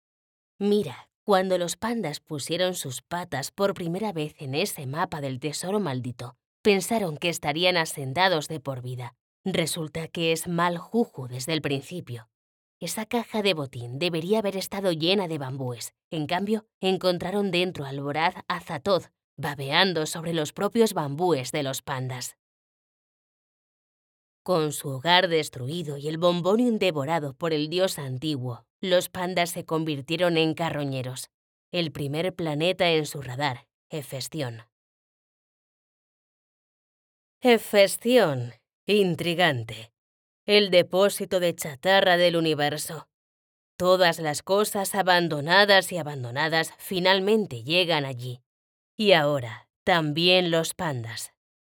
游戏旁白解说